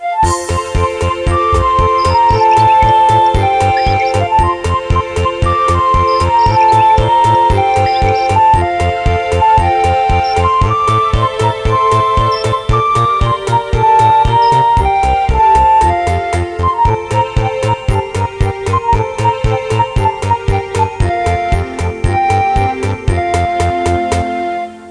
僕が探していたのは、朝の風景にふさわしい、爽やかで明るい曲でした。
ＢＧＭ 例１